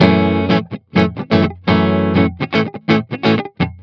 07 GuitarFunky Loop F.wav